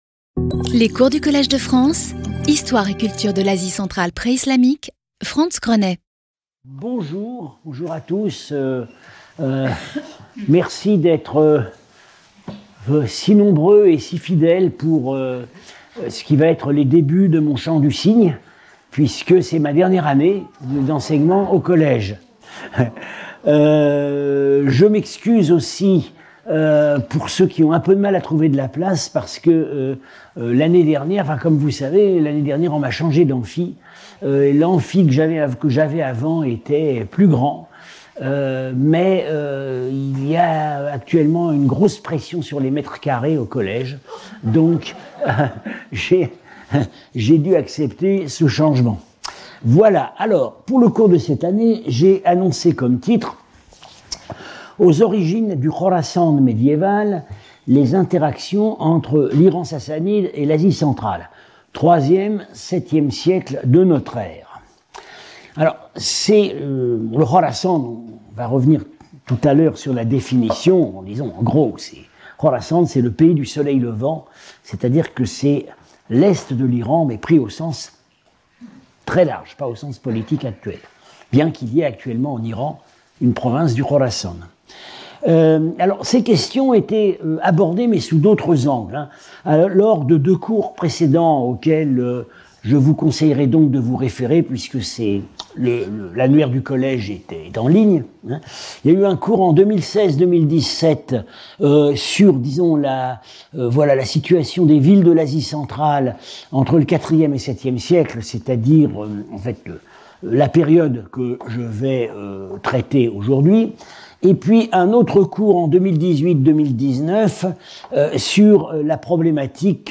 Professor at the Collège de France
Lecture